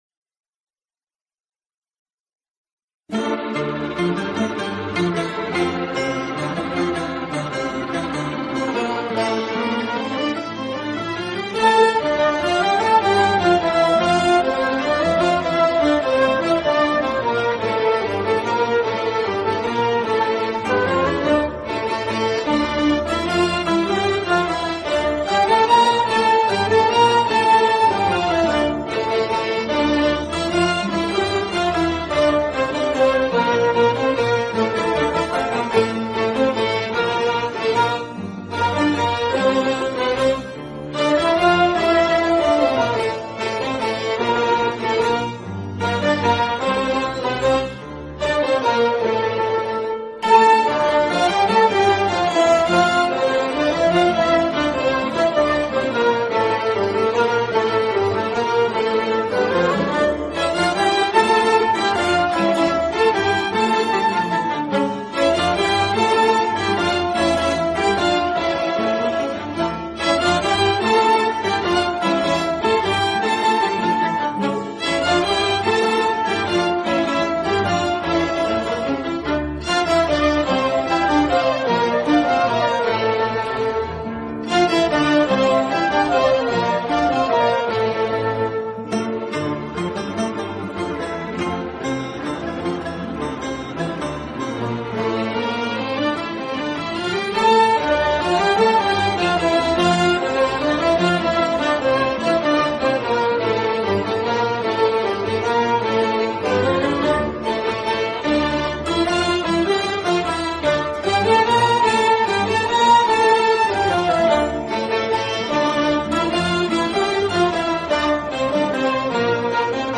بی‌کلام
خاطره انگیز ولی غمبار